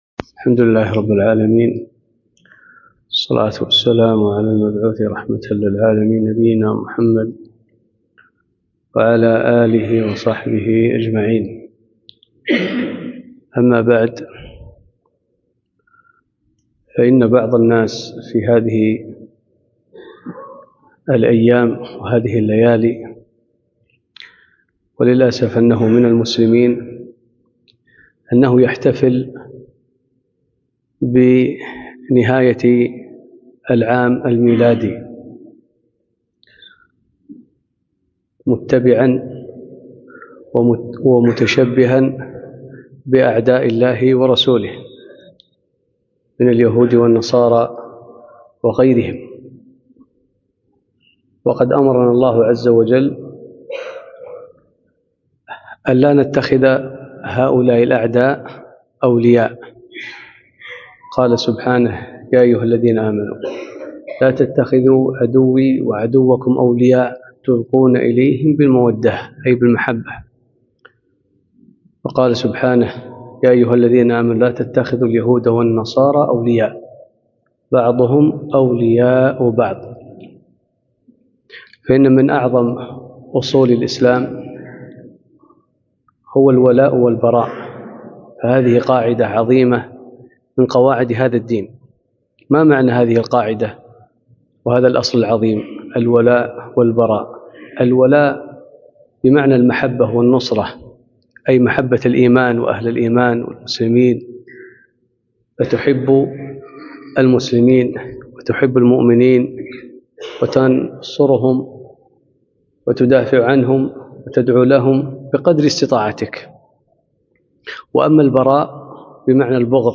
كلمة - حول الاحتفال بالعام الميلادي